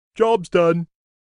Download Jobs Done sound effect for free.